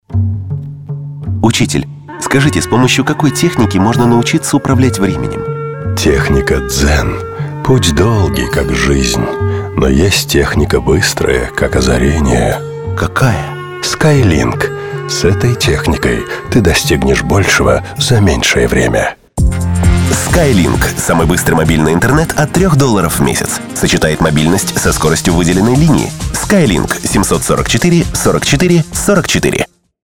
Креативное агентство BRANDSELL разработало радиоролик по заказу SKYLINK.